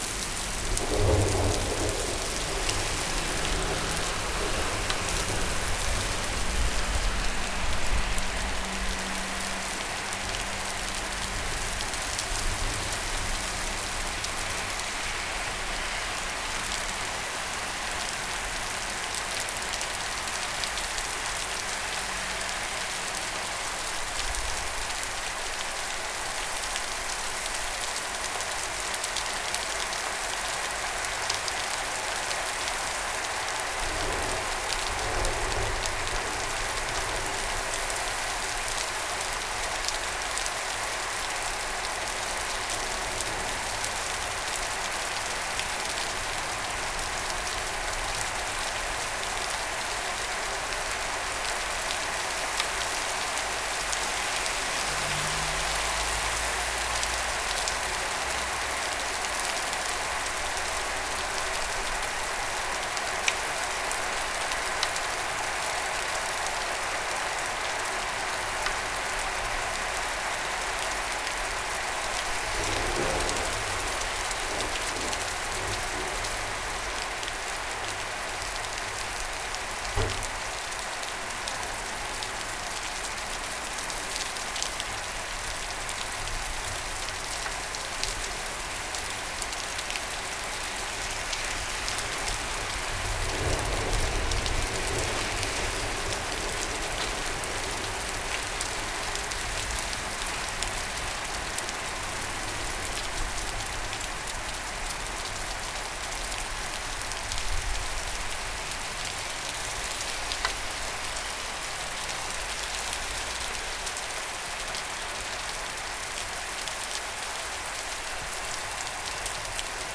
psr-lluvia-25.mp3